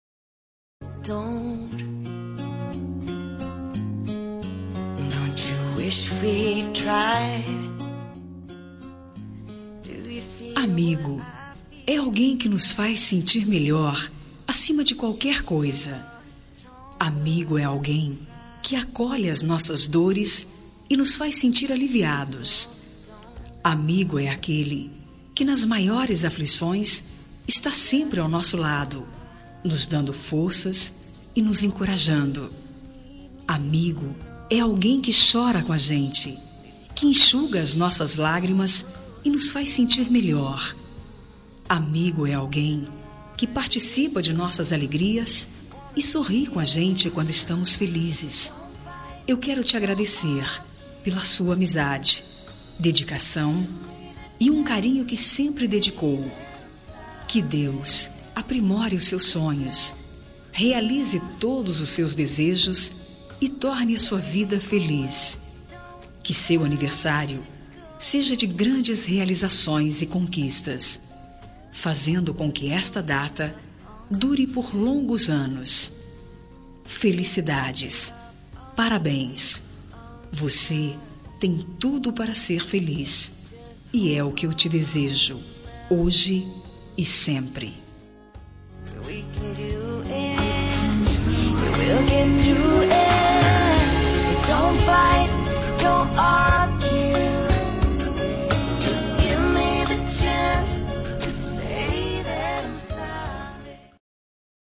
Telemensagem de Aniversário de Amigo – Voz Feminina – Cód: 1564 – Bonita